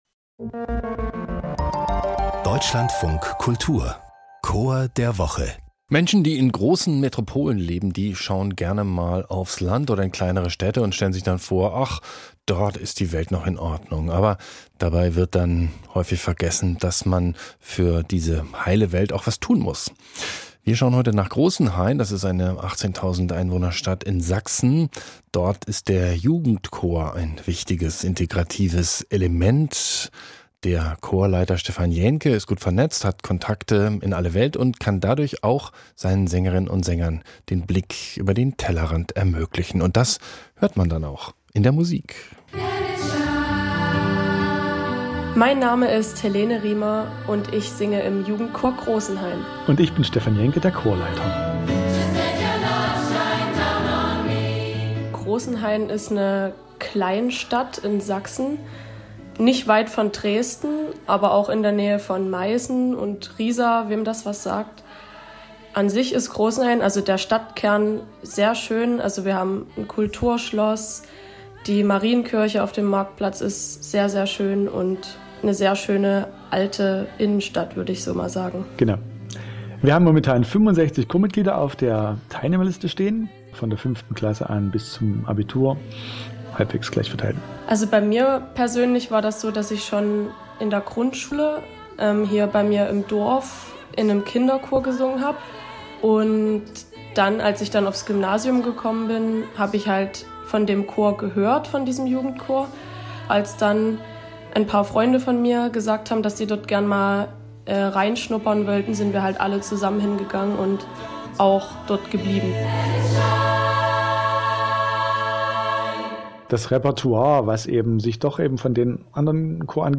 Jugendchor Großenhain